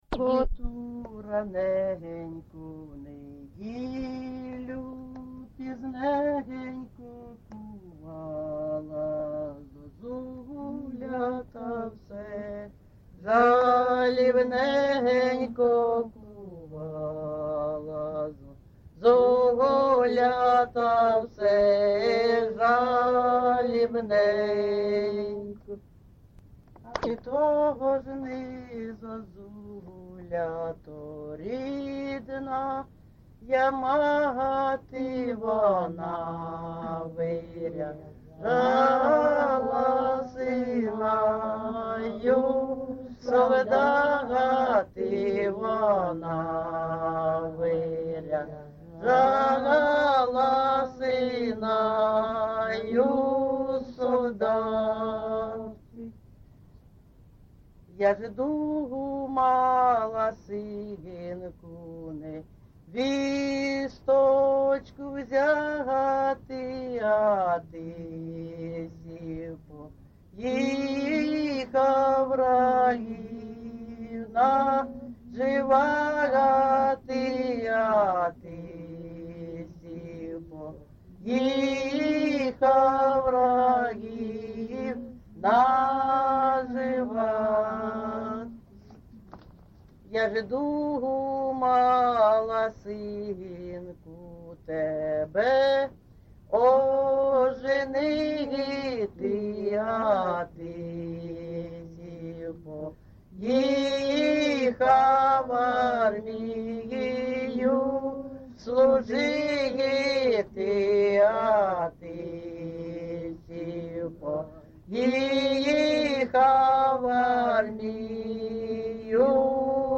ЖанрСолдатські
Місце записус. Андріївка, Великоновосілківський район, Донецька обл., Україна, Слобожанщина